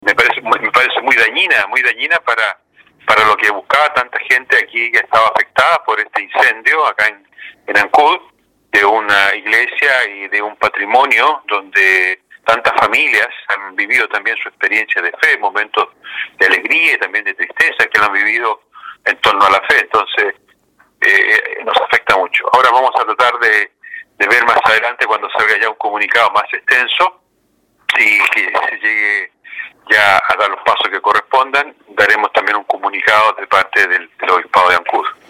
Lo anterior también fue analizado por la autoridad eclesiástica de Chiloé, Palena y las Guaitecas.